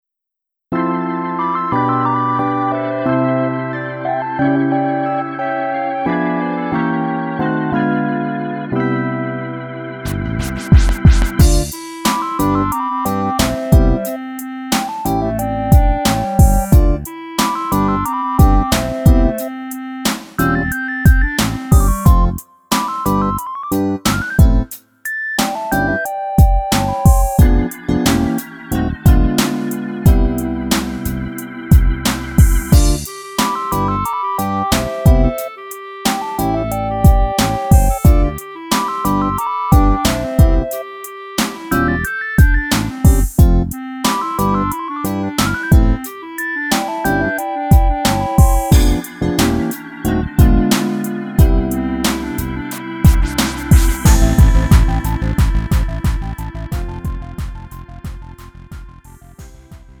음정 원키 3:46
장르 가요 구분 Lite MR